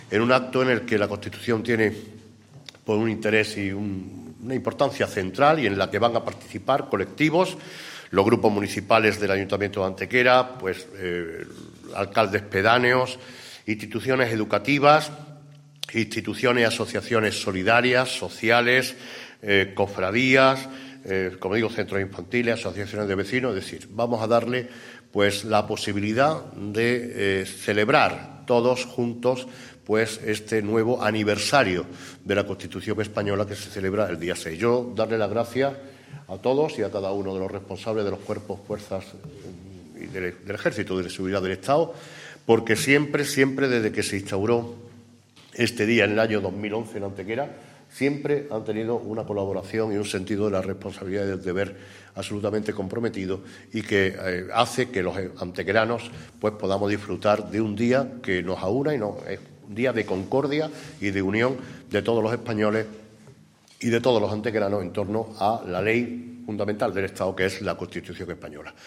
El alcalde de Antequera, Manolo Barón, ha informado hoy en rueda de prensa de la celebración, el próximo martes 6 de diciembre, del ya tradicional acto institucional en homenaje a la Constitución Española.
Cortes de voz